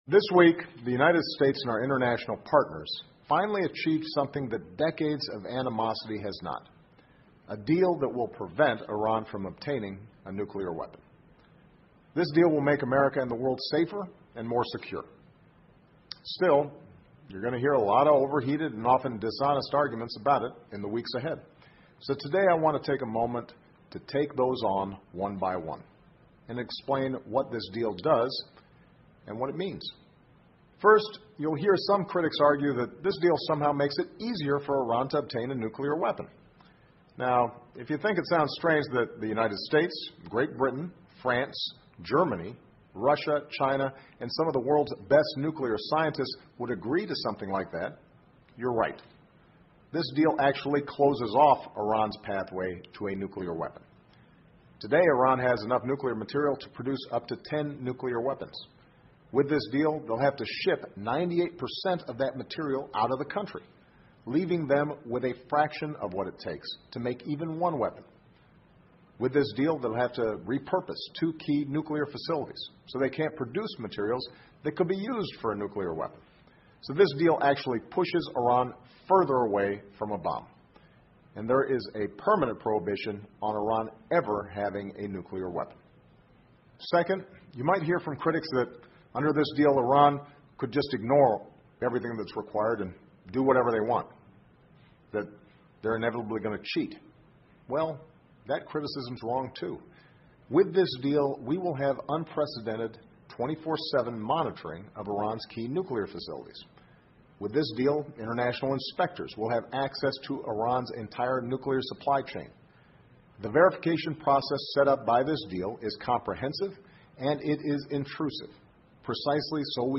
奥巴马每周电视讲话：总统就伊朗核协议发表讲话 听力文件下载—在线英语听力室